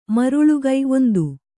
♪ maruḷugai